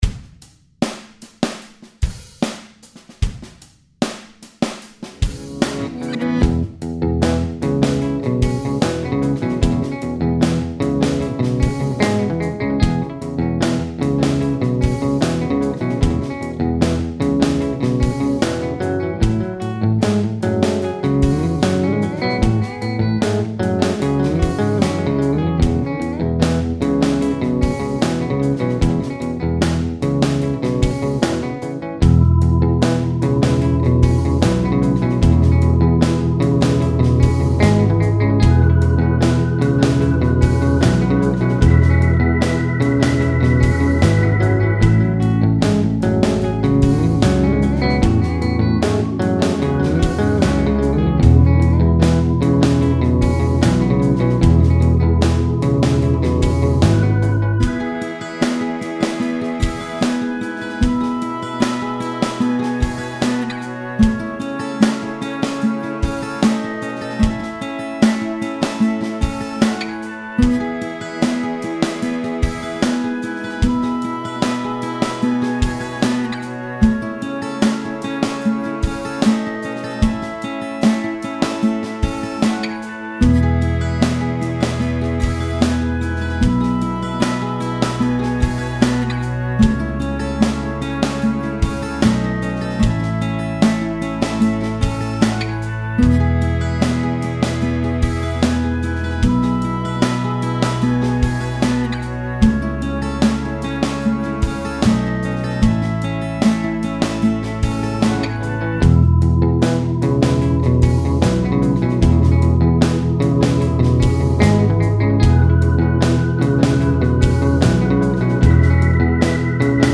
75 BPM